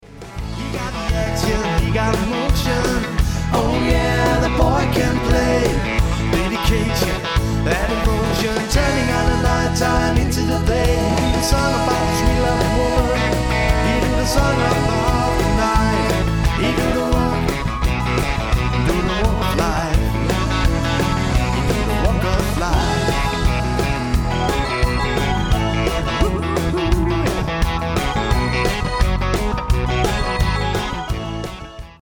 Professionel - Allround party band
• Coverband